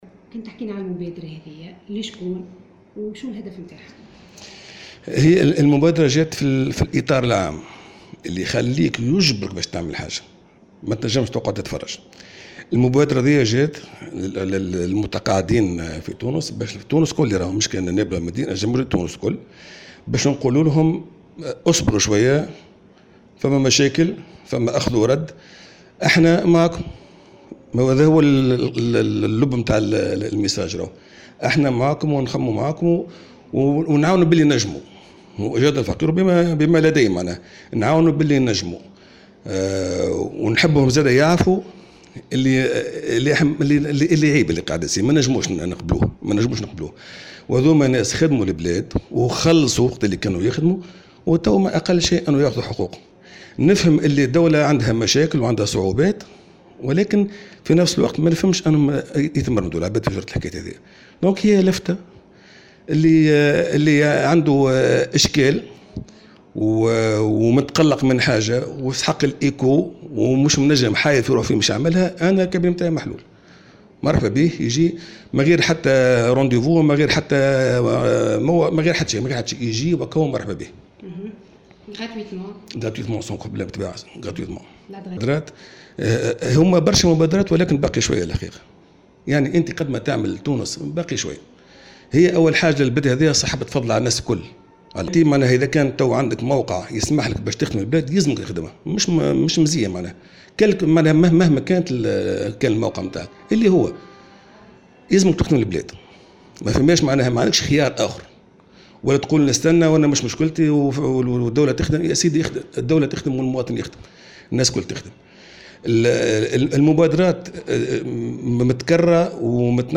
وقال في لقاء مع مراسلتنا إنه أعلن هذه المبادرة الرمزية من باب التضامن مع المتقاعدين غير القادرين على نفقات العلاج بعد تسجيل تأخير في تحويل رواتبهم في الفترة الأخيرة.